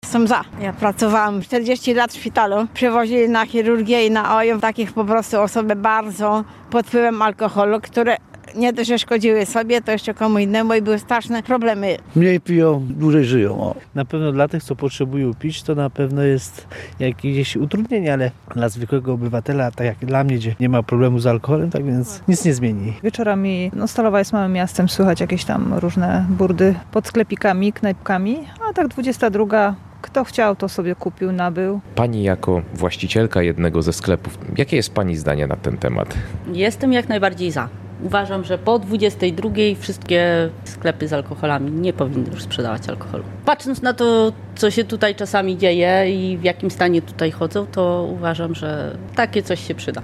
Sonda-o-prohibicji-w-ST-Woli.mp3